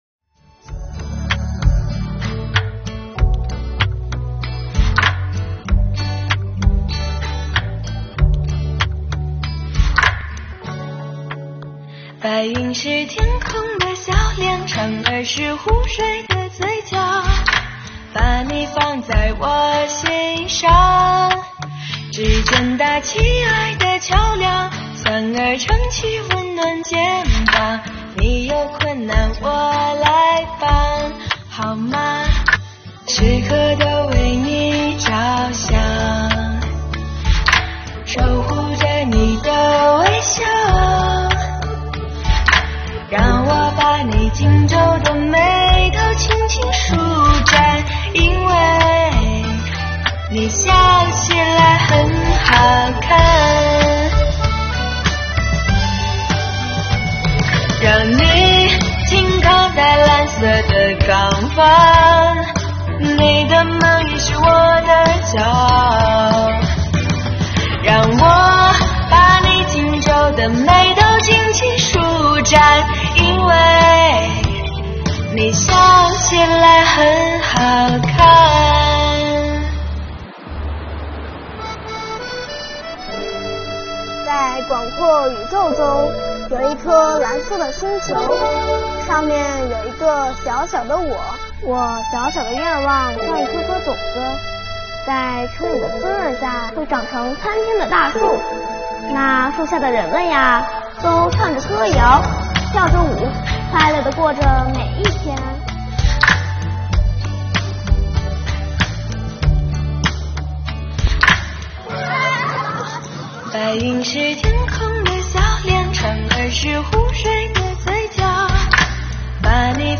2022年新年伊始，我们用税务人录制的歌曲，为大家制作了一本音乐挂历，并将全年办税时间做了标注，伴着歌声开启新征程吧。